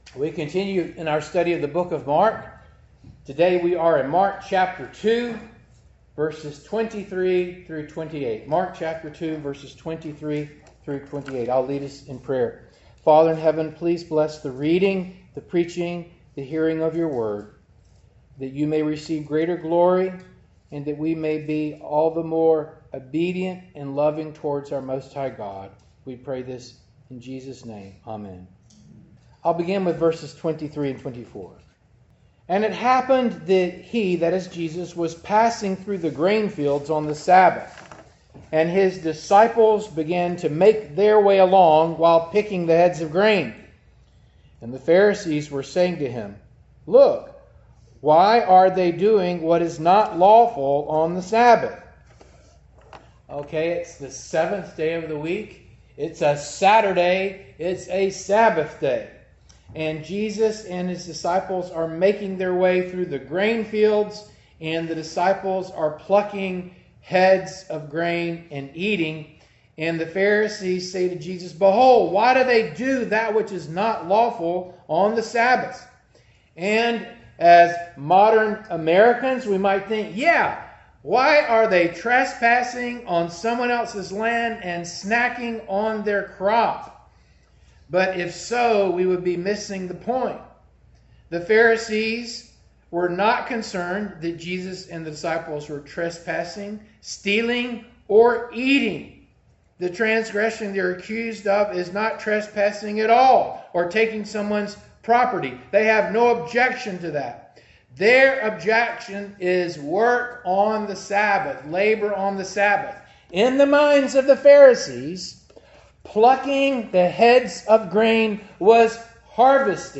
Passage: Mark 2:23-28 Service Type: Morning Service